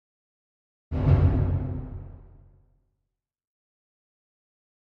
Drum Deep Symphonic - Orchestra Drum Double Hit